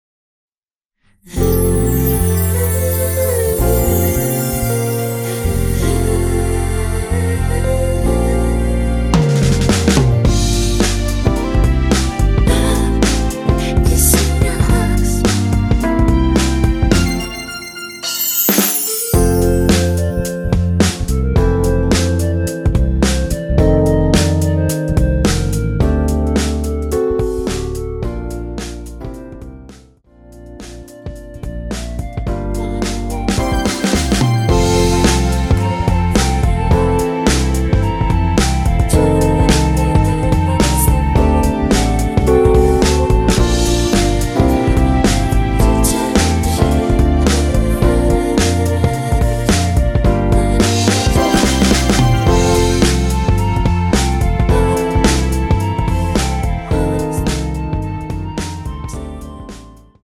원키 멜로디와 코러스 포함된 MR입니다.(미리듣기 확인)
앞부분30초, 뒷부분30초씩 편집해서 올려 드리고 있습니다.
중간에 음이 끈어지고 다시 나오는 이유는